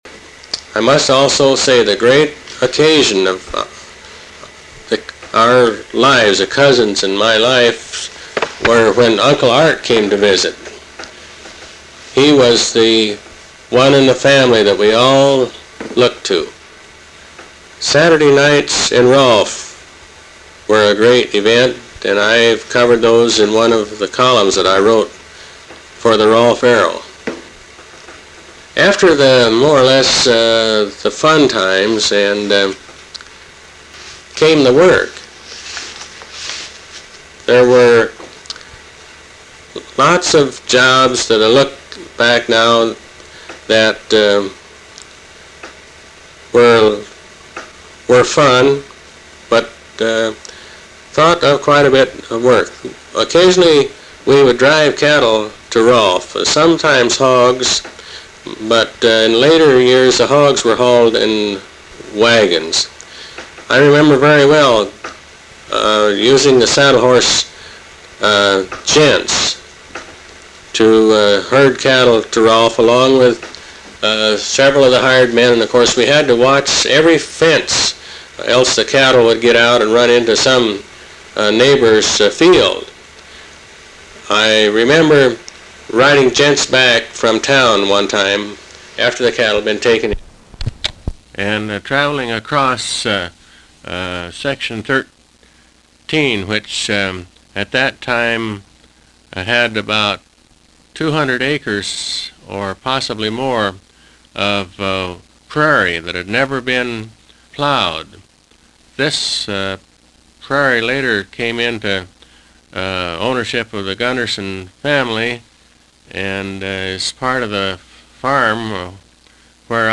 They are excerpts from one of his oral history tapes. In the clips he talked about work on the farm during his growing-up years, including his memories about threshing.